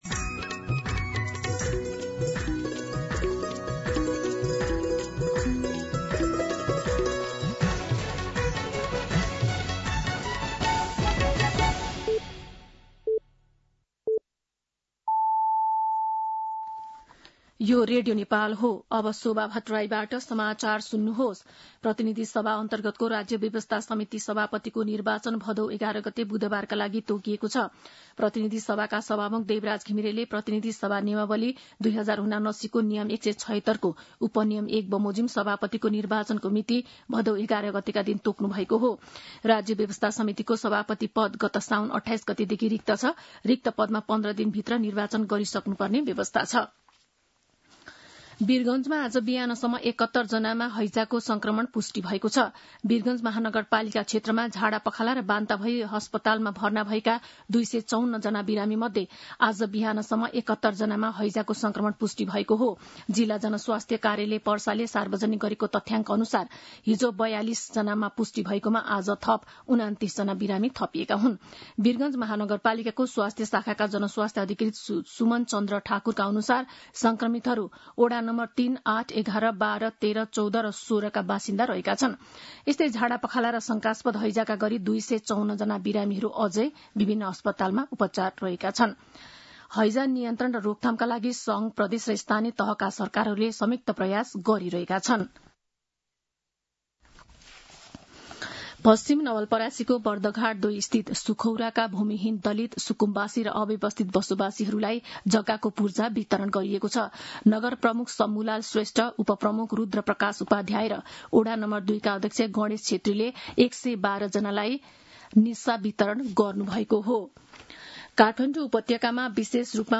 दिउँसो १ बजेको नेपाली समाचार : ९ भदौ , २०८२
1pm-News-09.mp3